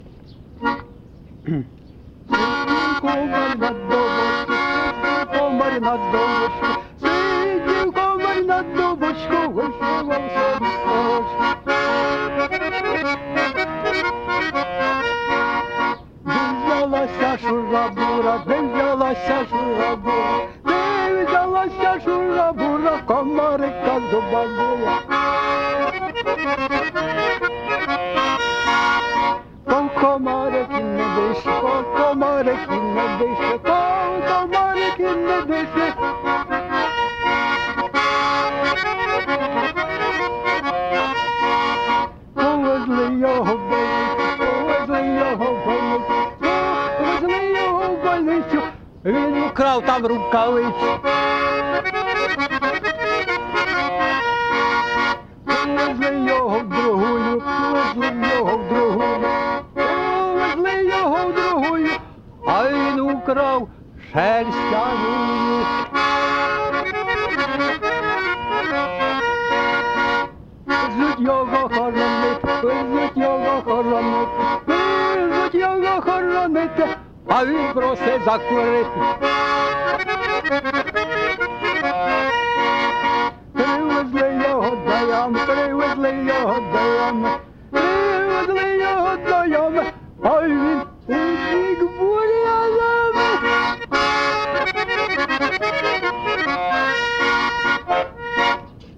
ЖанрІнструментальна музика, Жартівливі
Місце записус. Шийківка, Борівський район, Харківська обл., Україна, Слобожанщина
гармонь